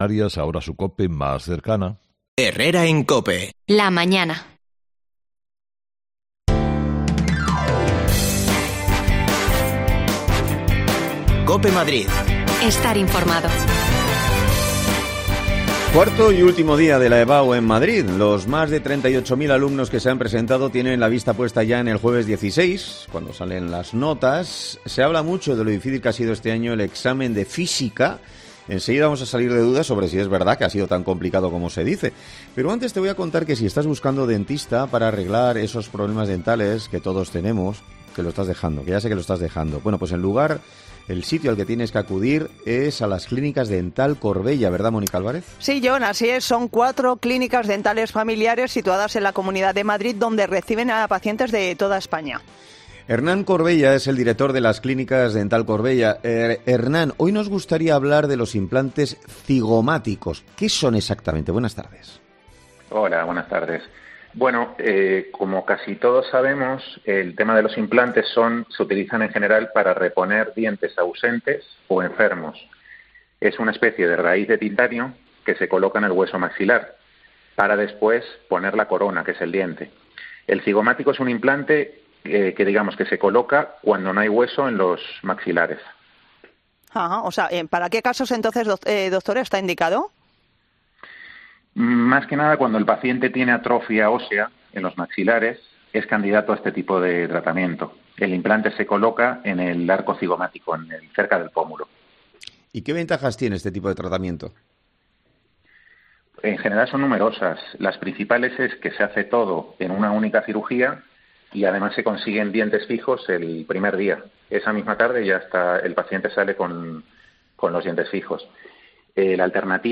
Se lo preguntamos a un profesor que imparte esta asignatura